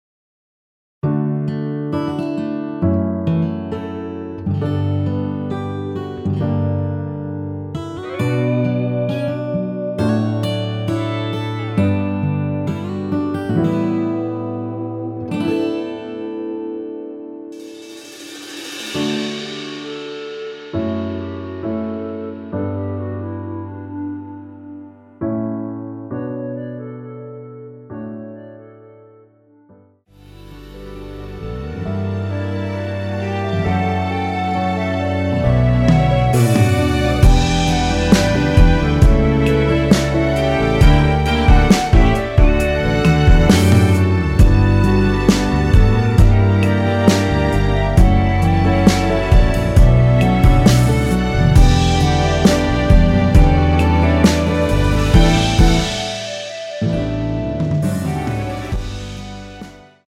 원키에서(-1)내린 멜로디 포함된 MR입니다.
앞부분30초, 뒷부분30초씩 편집해서 올려 드리고 있습니다.
위처럼 미리듣기를 만들어서 그렇습니다.